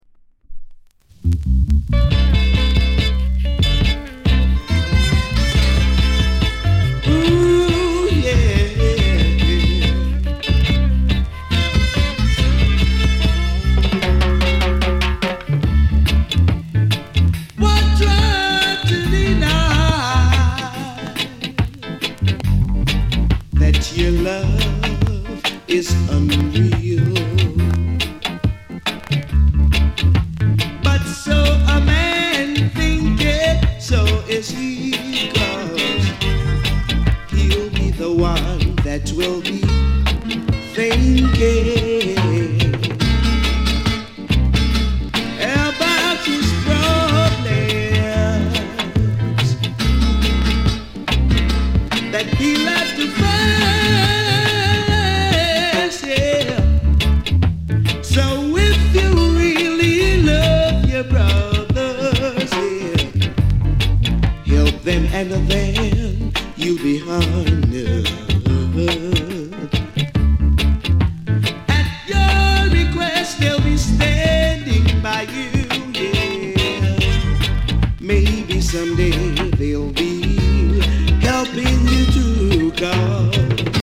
JA LOVERS ROCK！